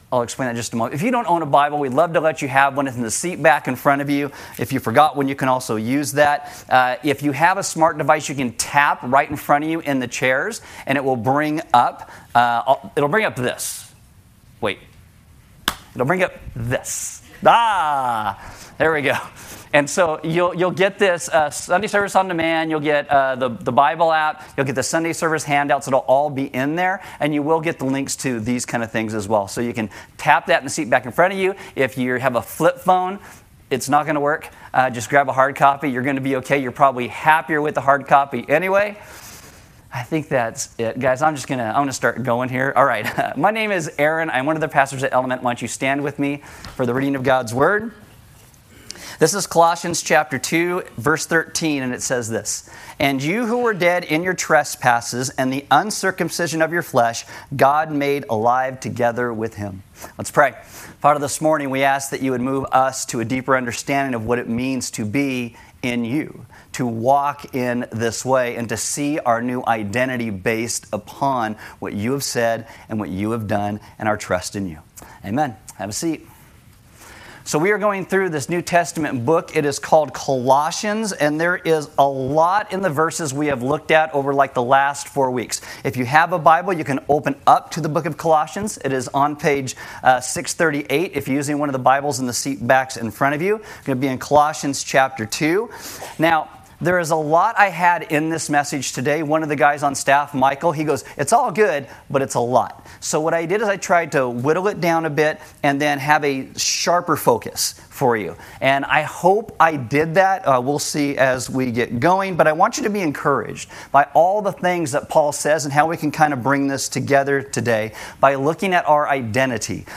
Service Audio God’s salvation is a gift, and once we receive this salvation, we should begin to see everything in life as a gift—even our faith.